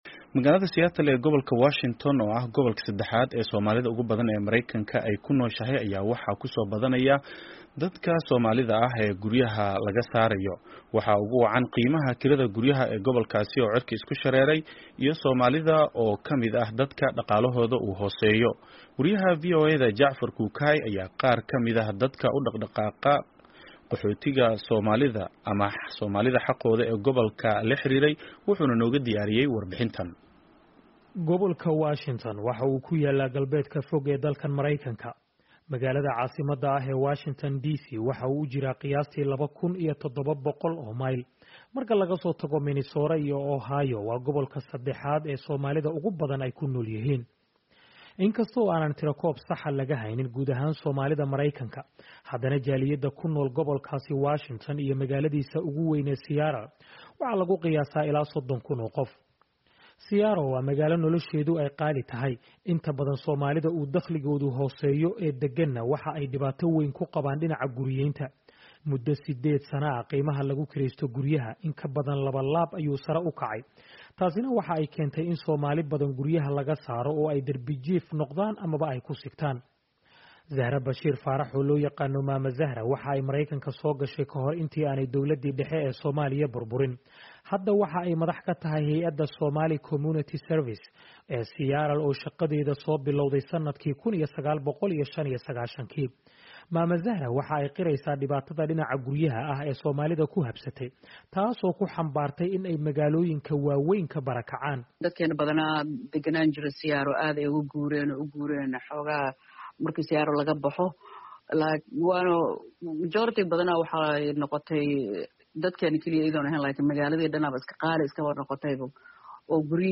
wuxuuna nooga diyaariyay warbixintan.